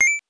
8 bits Elements / coin
coin_8.wav